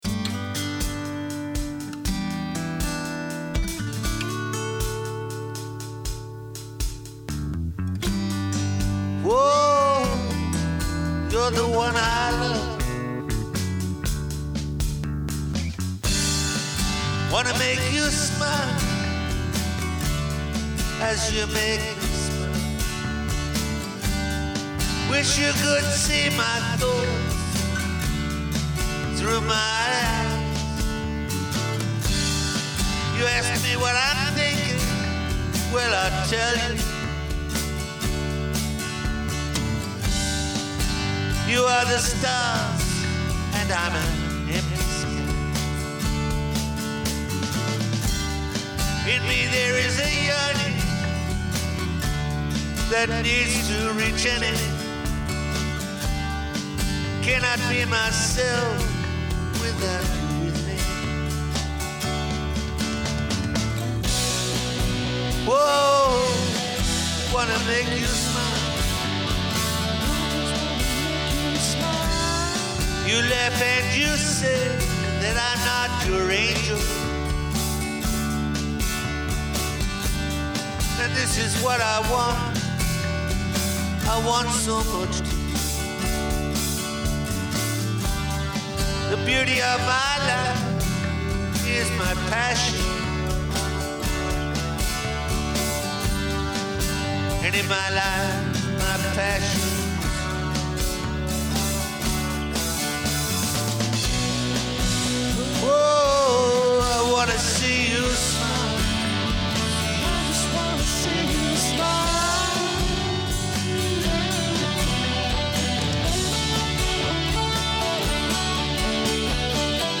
Guitar
Vocals
Drums
Recorded At MWEMUSIC